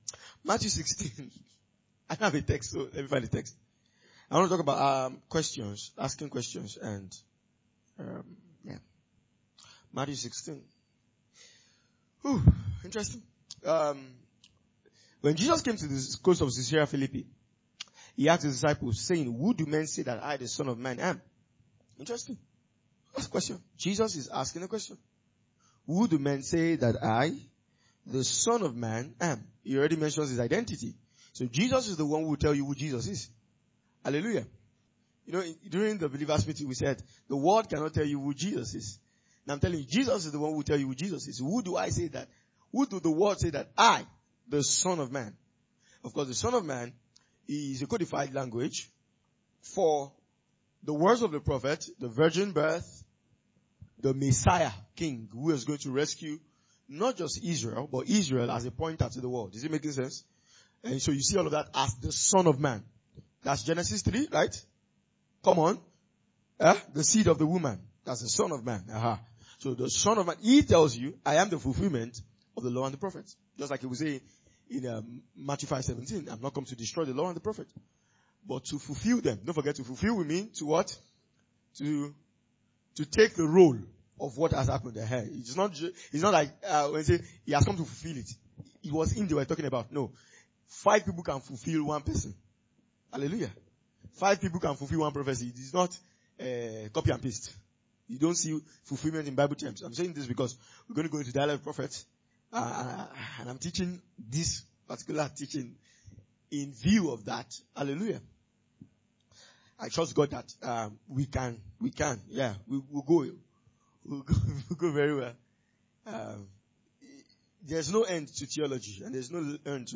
2025 Glorious House Church Teachings.